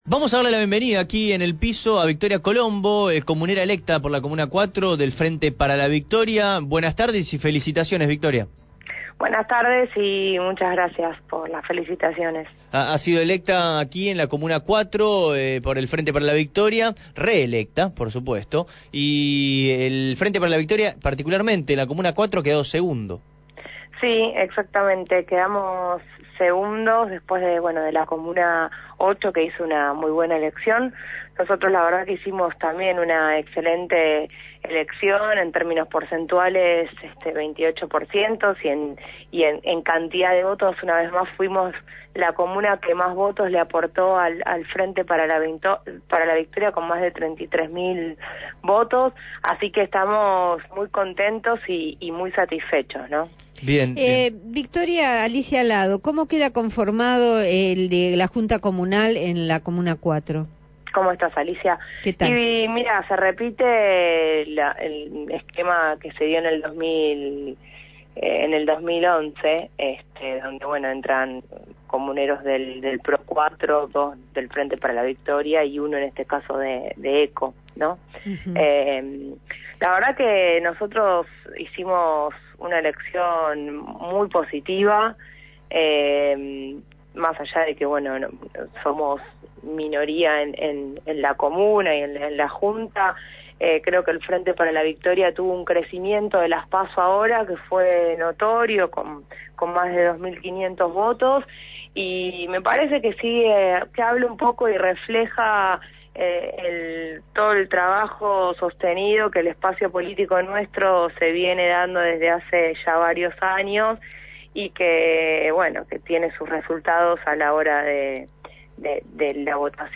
Victoria Colombo, reelecta en la Comuna 4 por el Frente Para la Victoria y referente de La Cámpora fue entrevistada en Abramos la Boca tras las elecciones en la Ciudad de Buenos Aires.